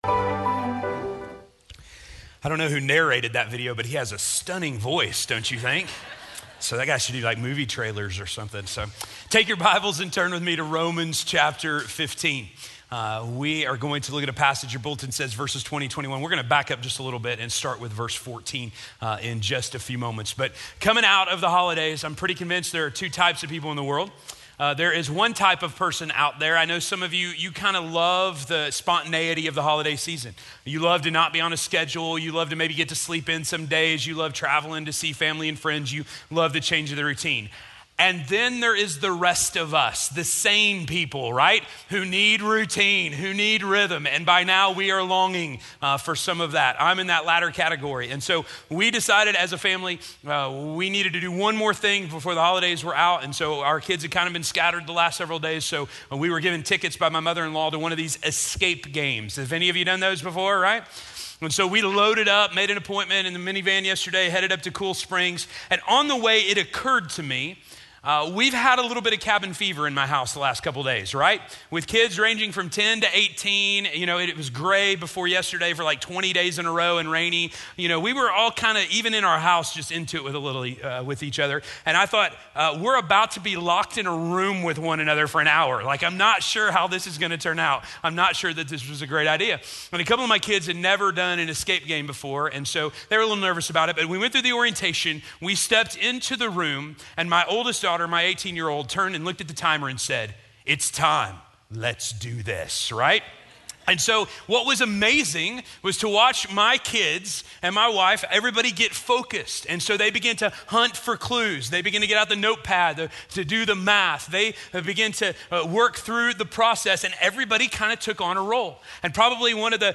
Where the Gospel Still Needs to Be Preached - Sermon - Station Hill